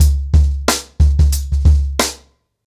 TrackBack-90BPM.33.wav